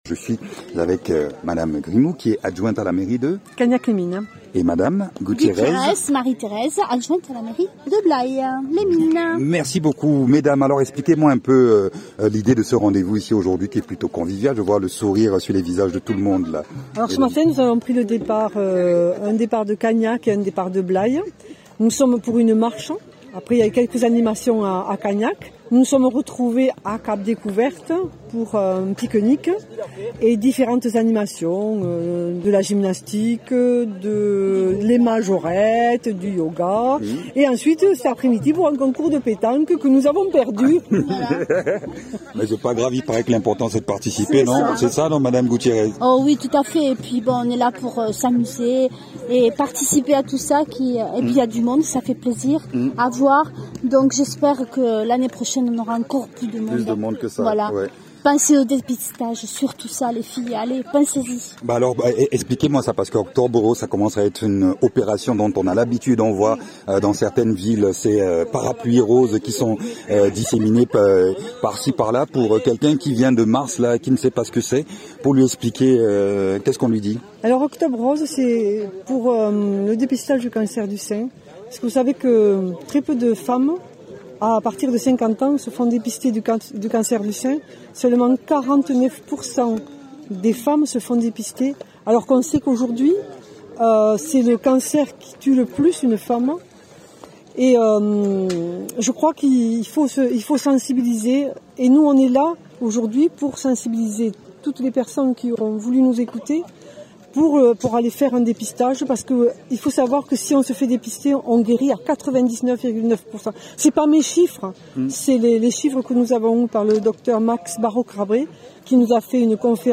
Interviews
Invité(s) : Mme Hélène Grimaud, adjointe à mairie de Cagnac-les-mines ; Mme Marie-Thérèse Gutierrez, adjointe à la mairie de Blaye-les-mines.